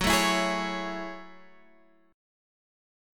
F#m9 chord {x 9 7 9 9 9} chord